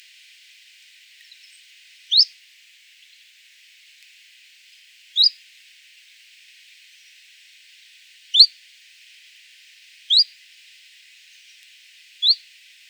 Phylloscopus collybita
E 11°05' - ALTITUDE: +170 m. - VOCALIZATION TYPE: contact call. - SEX/AGE: unknown - COMMENT: A slightly different call variant: an irregular hooked shape and no visible harmonics.
Time interval between calls has been reduced in this audio sample (see spectrogram time axis).